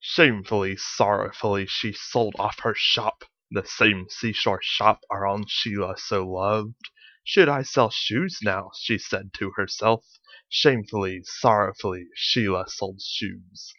Practice (second part)